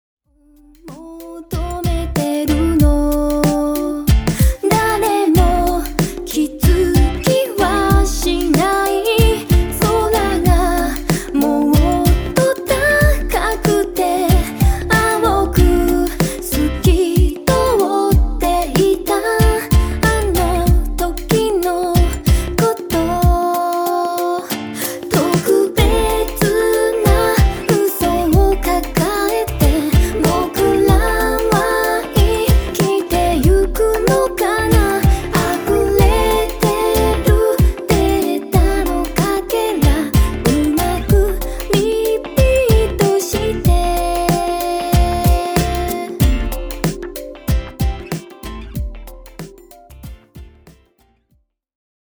Featuring Vocalists :